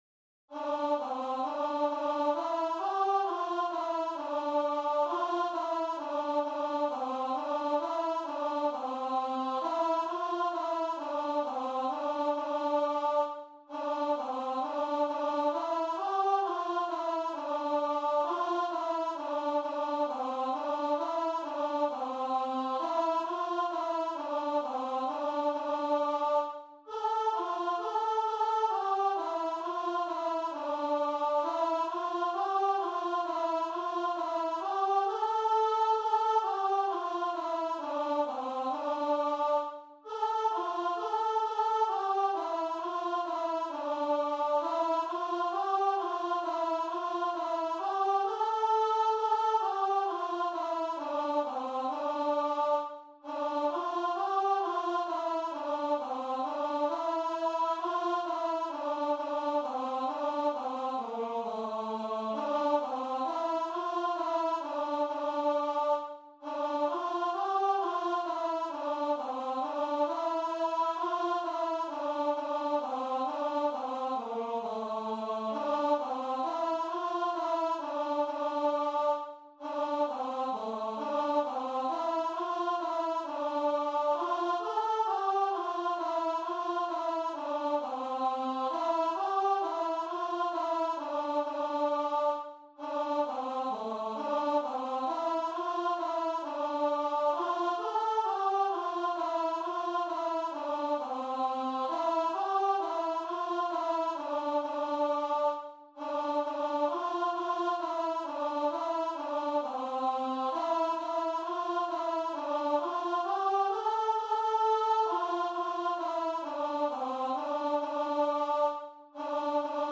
Free Sheet music for Voice
Traditional Music of unknown author.
C major (Sounding Pitch) (View more C major Music for Voice )
4/4 (View more 4/4 Music)
M.M. =c.132
A4-A5
Voice  (View more Easy Voice Music)
Christian (View more Christian Voice Music)